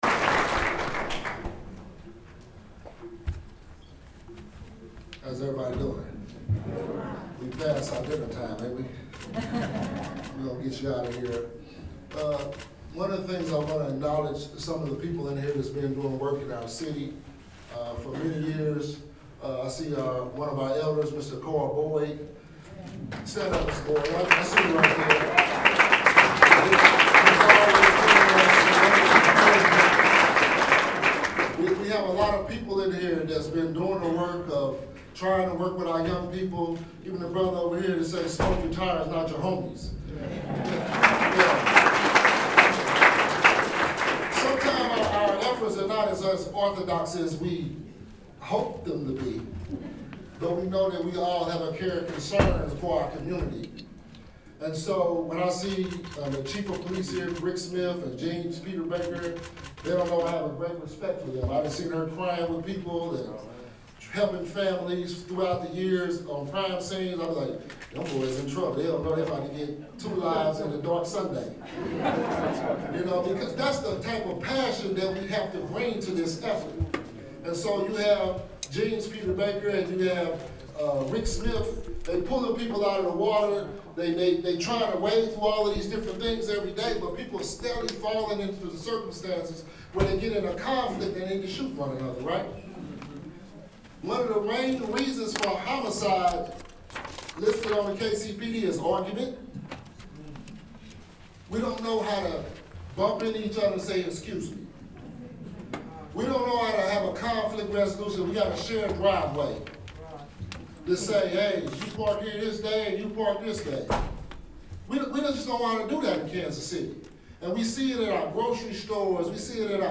Wednesday evening on the Penn Valley campus of the Metropolitan Community Colleges Representative Emanuel Cleaver (D) hosted a town hall of sorts on gun violence. The community event included elected officials, law enforcement officials, and leaders of community groups.